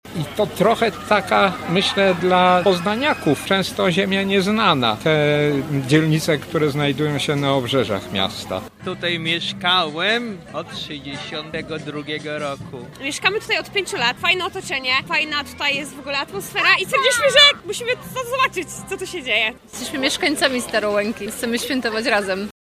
- mówili mieszkańcy.